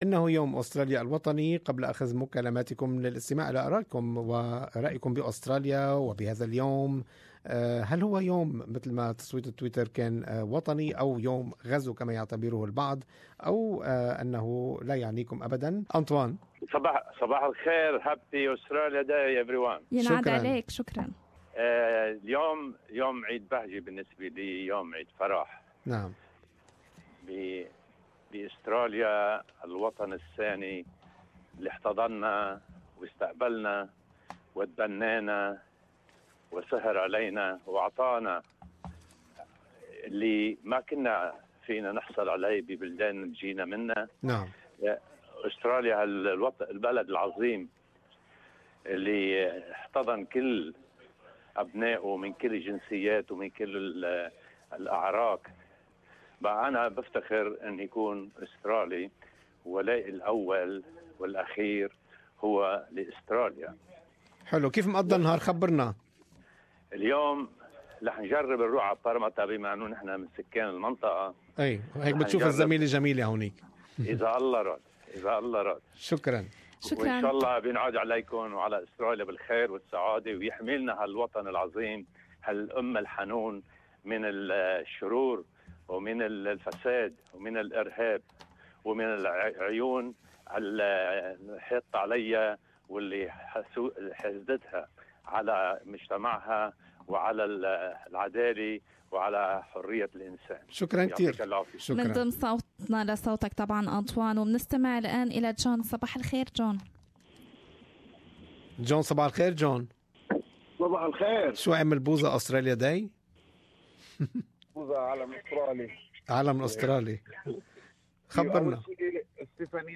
Good Morning Australia listeners share their opinions with us.